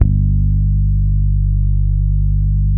NO FRET .1-R.wav